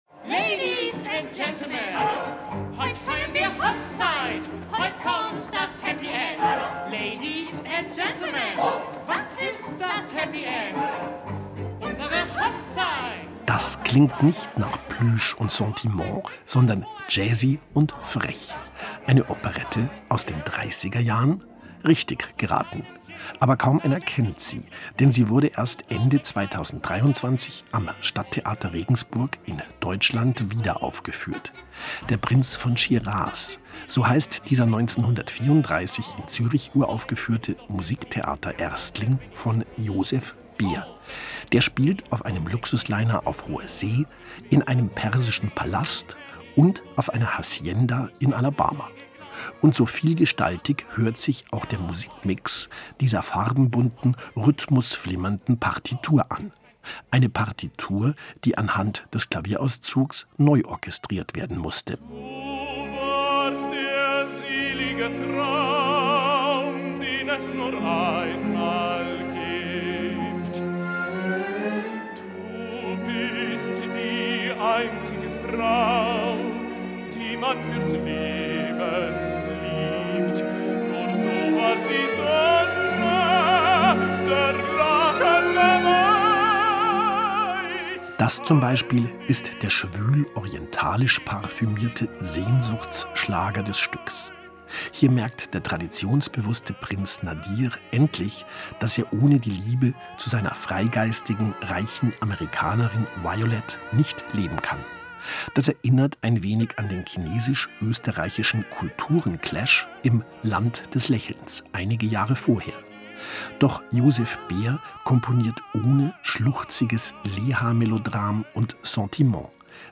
Album-Tipp
Farbenbunte aber unbekannte Operette
Und so vielgestaltig hört sich auch der Musikmix dieser farbenbunten, rhythmusflimmernden Partitur an.